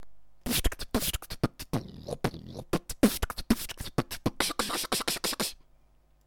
Форум российского битбокс портала » Реорганизация форума - РЕСТАВРАЦИЯ » Выкладываем видео / аудио с битбоксом » Мои биты (Сюда быду выкладывать все известные мне биты)
вместо grb я используя просто хрипение без голоса